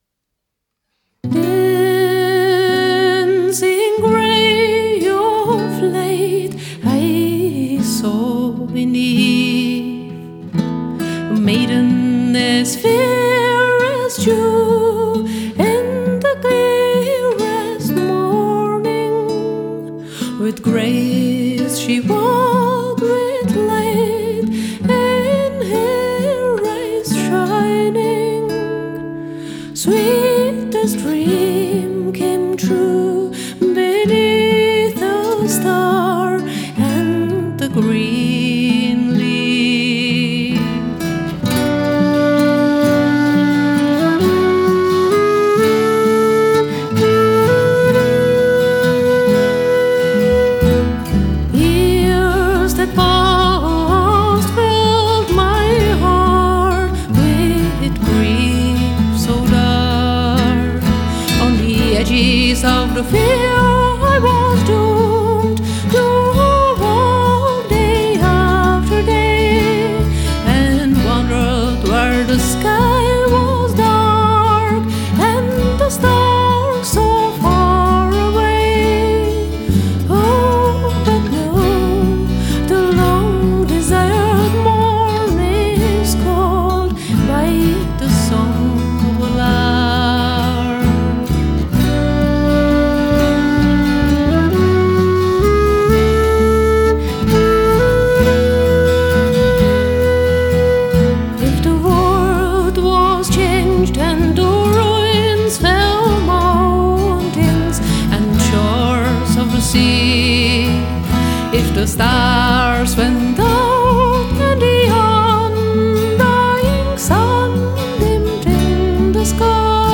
Romantická Milostná Elfská Píseň
irská tradiční
zpěv, kytara
příčná flétna
basová kytara
nahráno ve studiu